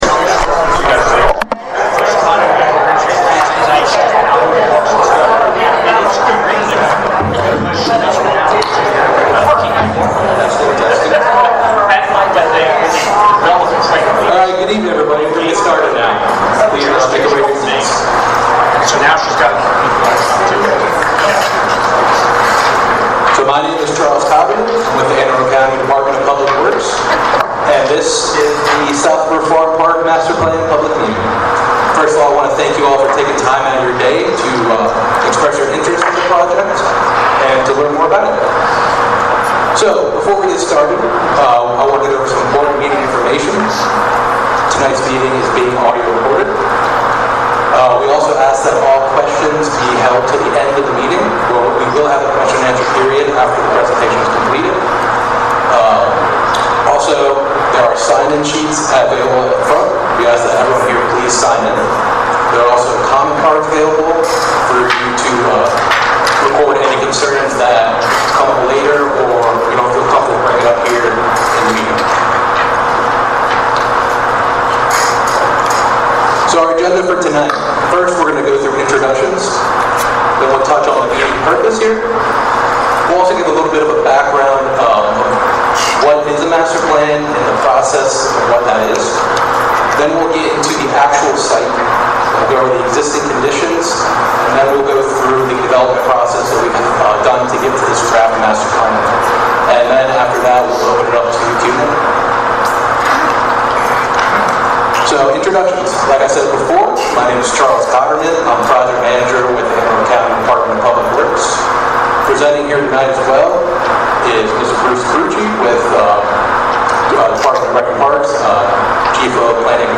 South River Farm Park Final Master Plan - 2024 South River Farm Park Master Plan - 2018 South River Farm Park Master Plan Public Meeting Presentation - 06/27/2024 South River Farm Park Master Plan Public Meeting Audio 06/27/2024